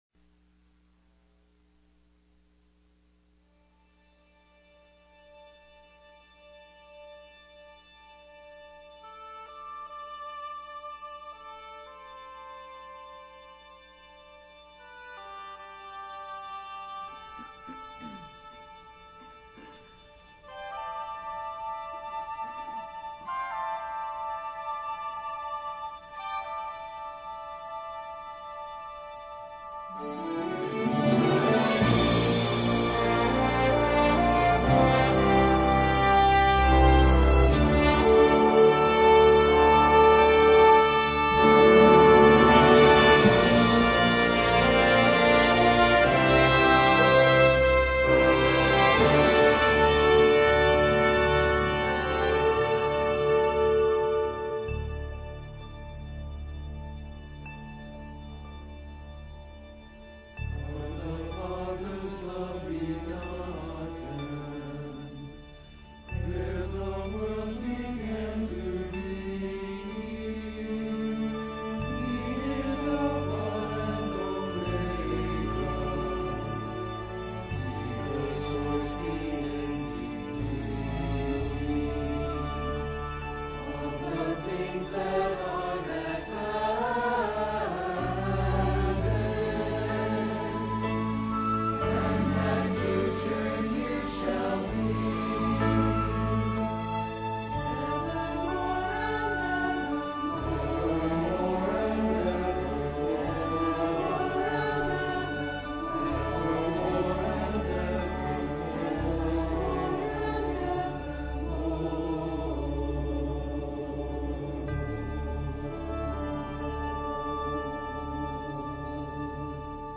Easter Cantata